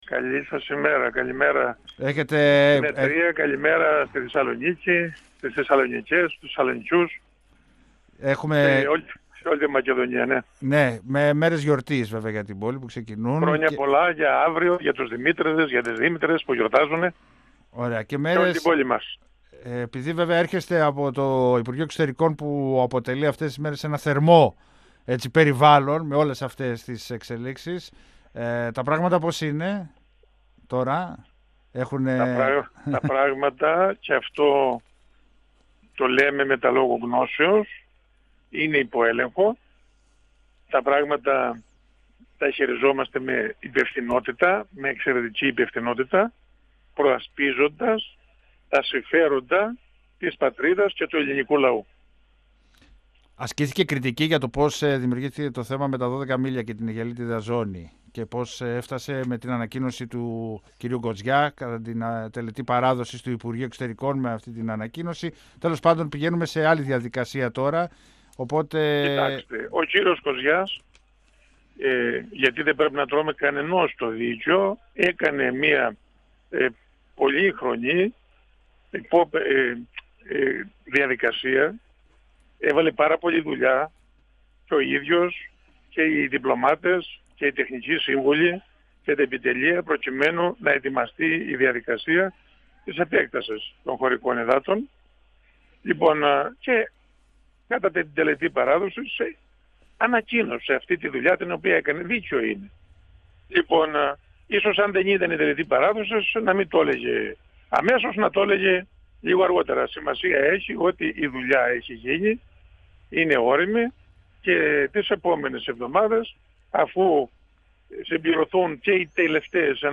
Ο υφυπουργός Εξωτερικών, Μάρκος Μπόλαρης, στον 102FM του Ρ.Σ.Μ. της ΕΡΤ3